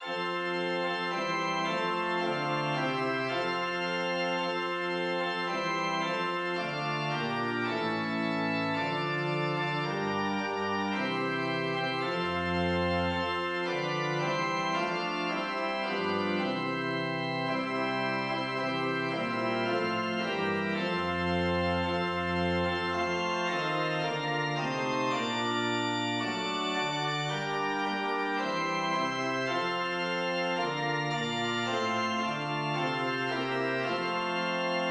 GenreHymn
Meter7.7.7.7 D